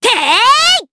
Cecilia-Vox_Attack4_jp.wav